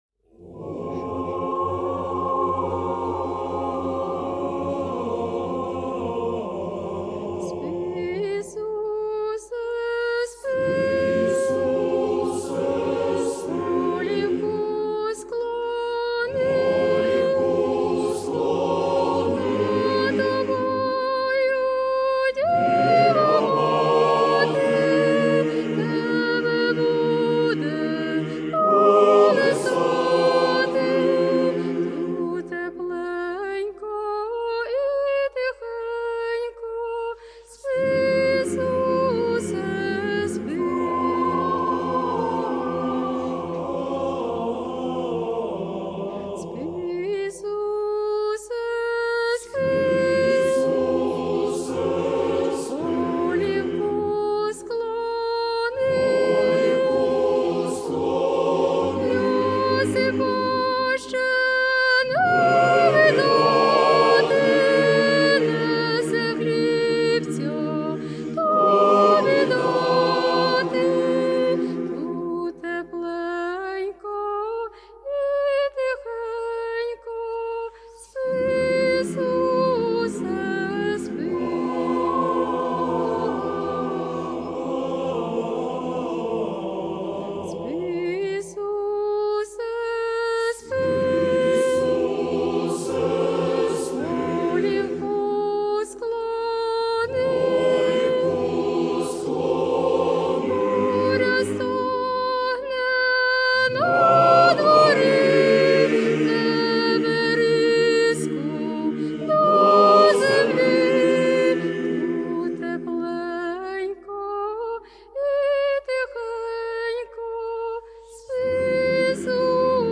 Рождественские колядки
Хор Почаевской Лавры